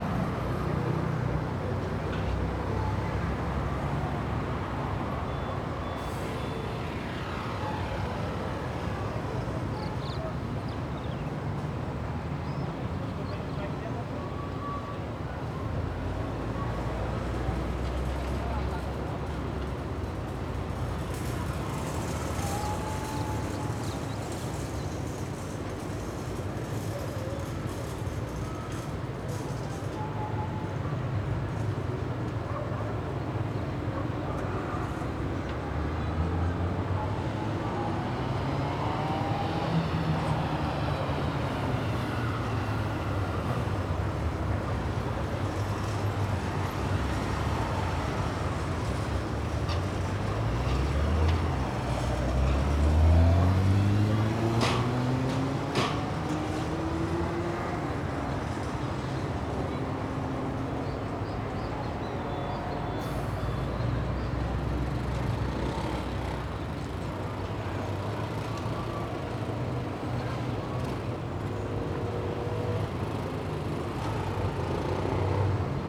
Ambiencia em Estrada de Terra na Chapada dos Veadeiros com Algum Vento
Vento forte
Surround 5.1